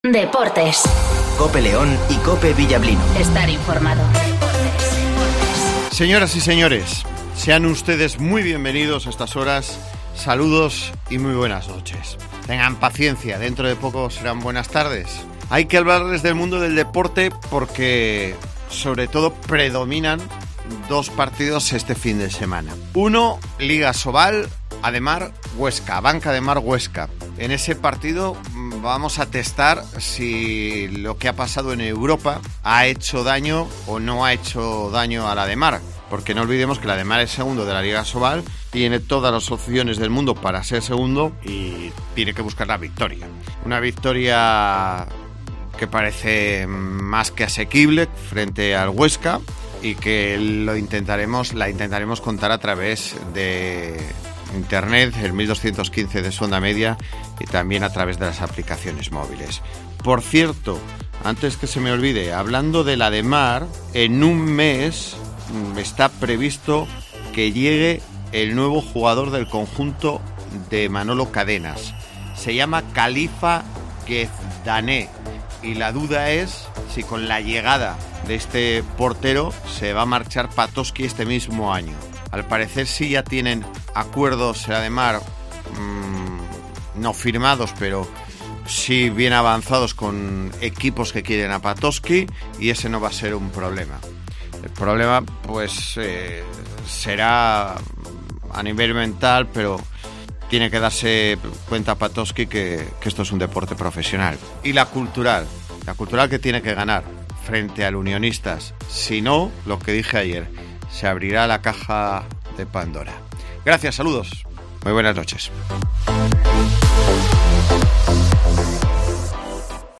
Escucha la Información Deportiva de las 20:58 h en Cope León ( 14-02-20 )